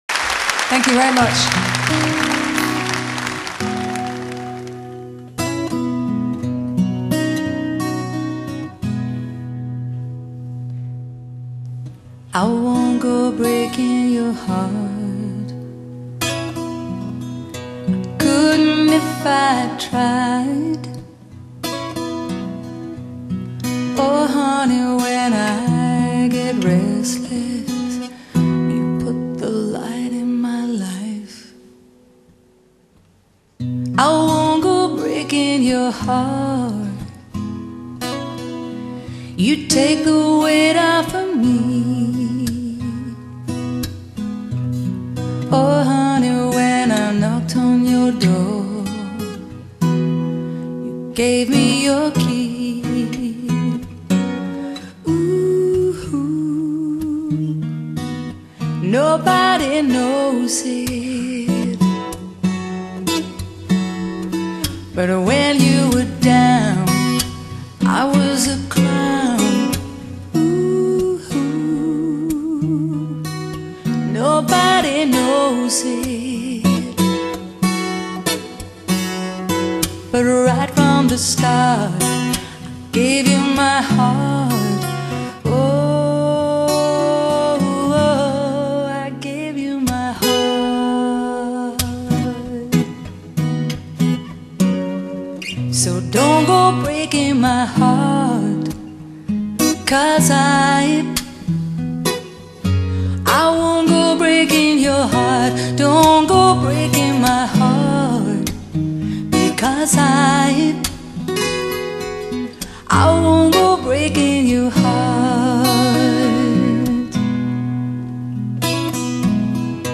以清脆玲瓏的吉他聲響伴奏，感情真摯地唱出13首精彩作品
如果不是聽到觀衆的掌聲營造寬廣的空間效果，這麽透明、純淨的聲音，很難讓人相信這是一張現場的錄音
都是朗朗上口的曲子